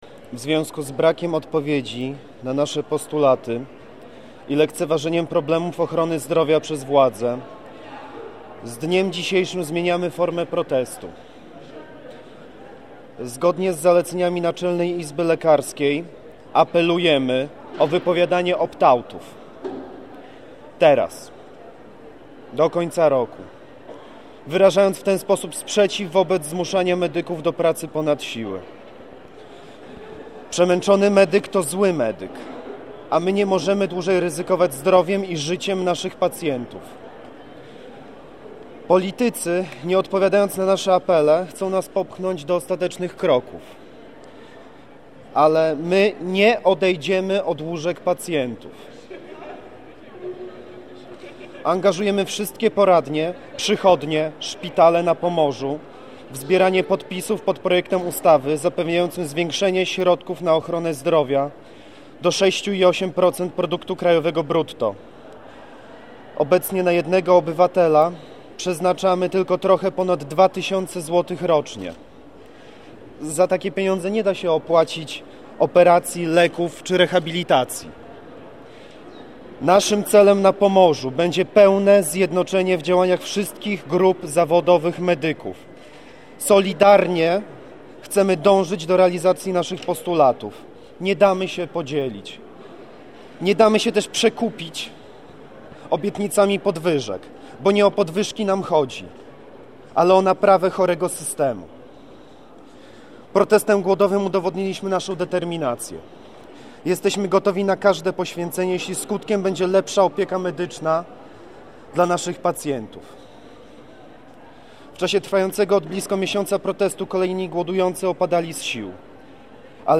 Lekarz przeczytał dziś dziennikarzom oświadczenie protestujących: /audio/dok2/koniec_glodowki.mp3 Lekarze podkreślają, że umowy opt-out miały być forma przejściową, wprowadzoną kiedy Polska wstępowała do Unii Europejskiej.